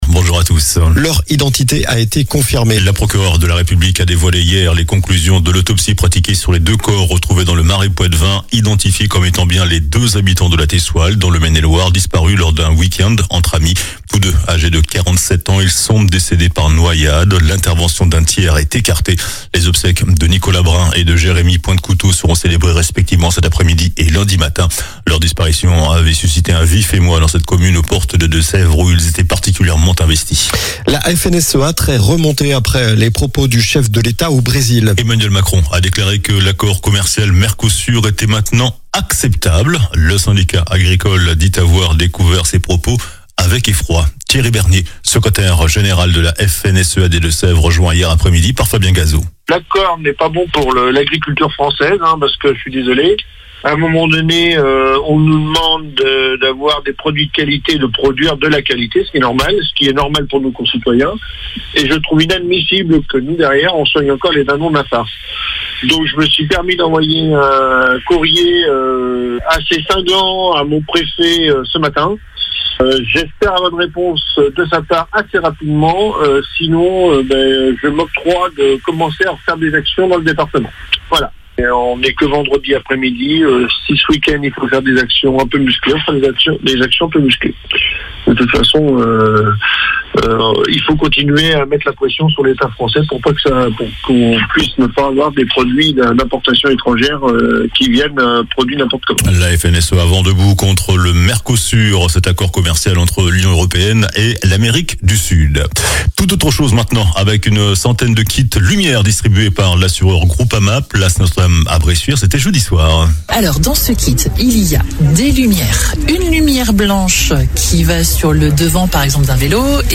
JOURNAL DU SAMEDI 08 NOVEMBRE